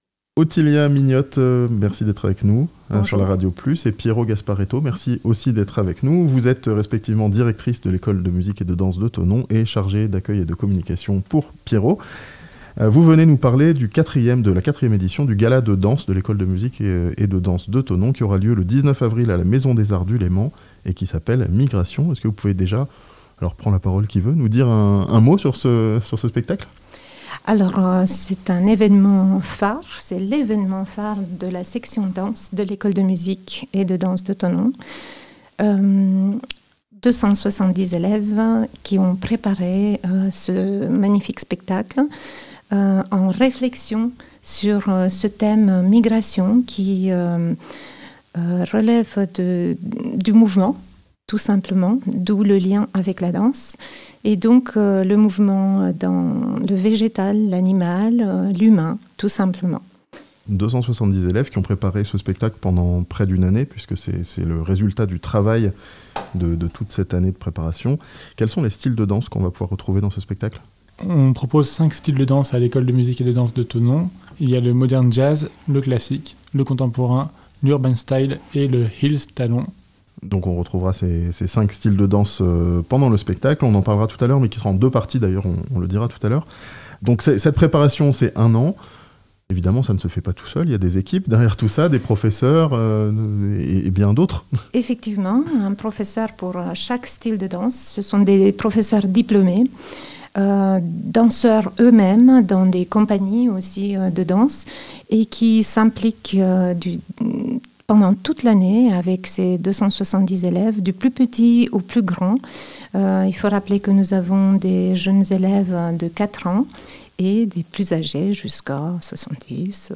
L'école de musique et de danse de Thonon présentera son gala "Migrations" le 19 avril (interview)